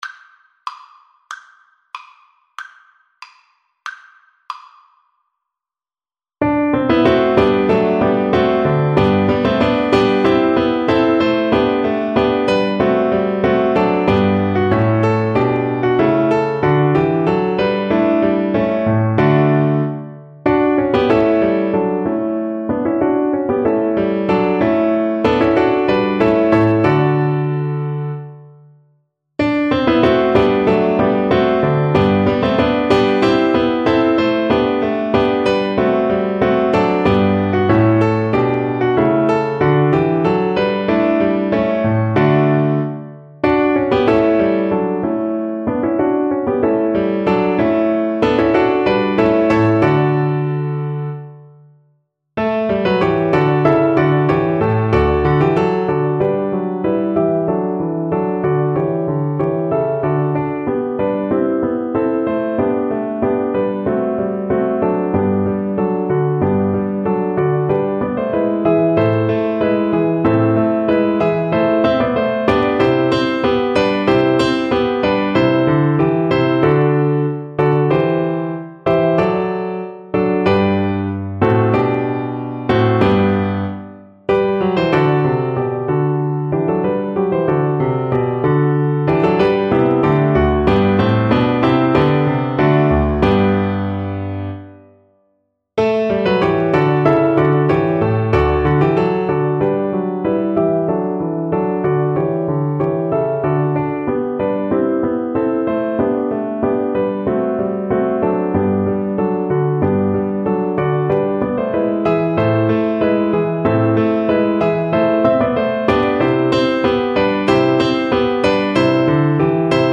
Free Sheet music for Cello
Cello
2/4 (View more 2/4 Music)
G3-G5
G major (Sounding Pitch) (View more G major Music for Cello )
II: Allegro =94 (View more music marked Allegro)
Classical (View more Classical Cello Music)